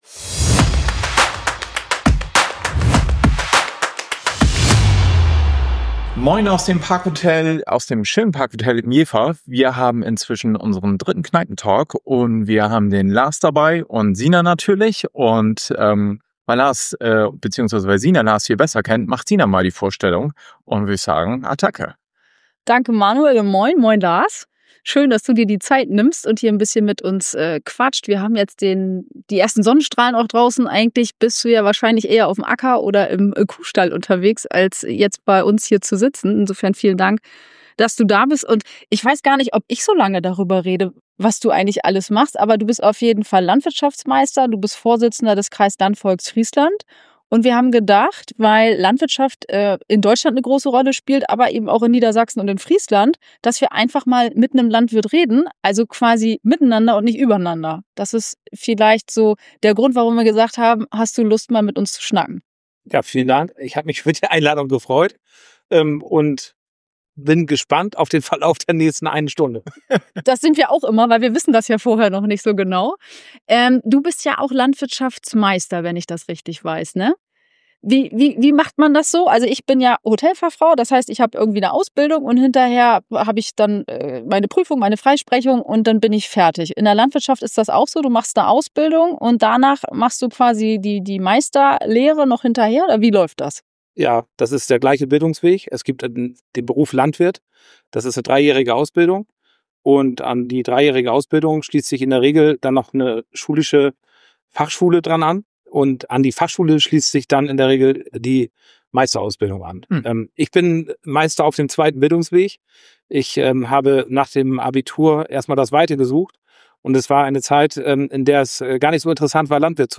Es wird diskutiert, gelacht und auch mal gestritten – ohne Skript, aber mit Haltung. Ungefiltert, nah dran und immer mit einer Prise Kneipenflair.